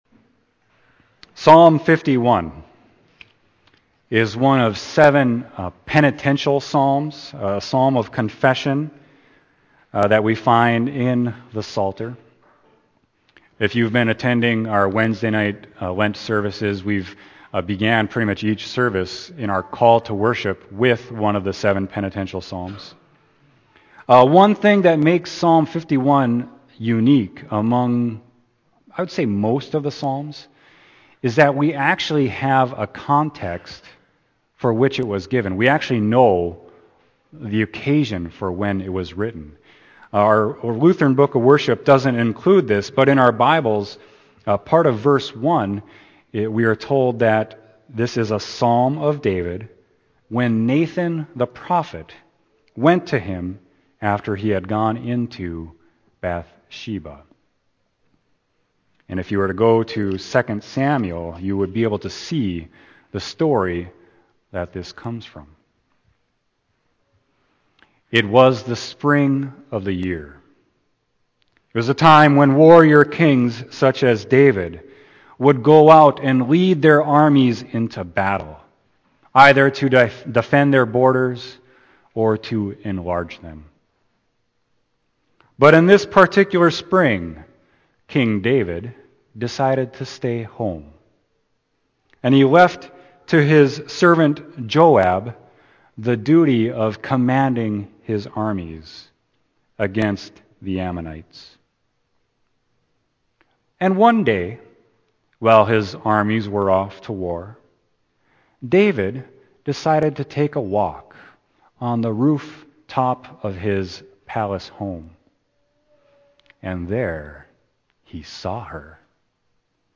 Sermon: Psalm 51.1-12